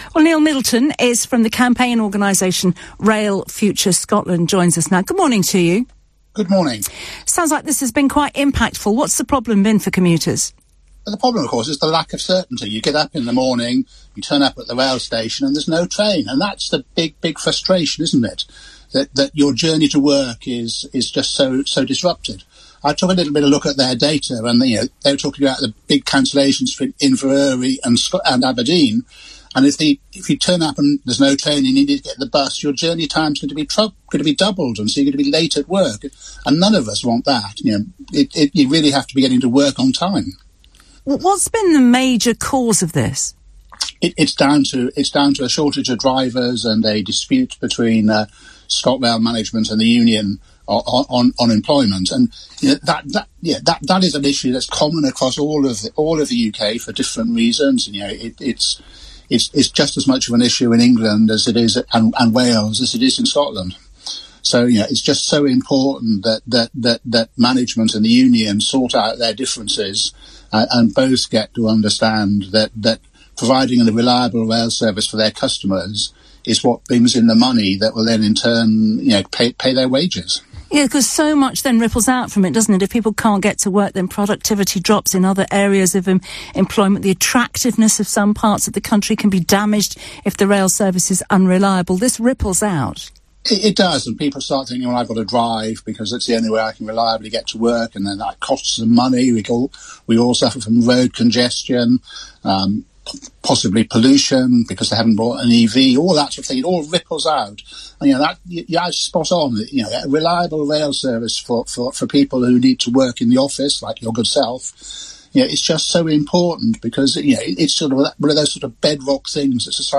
appears on the LBC News breakfast show to discuss.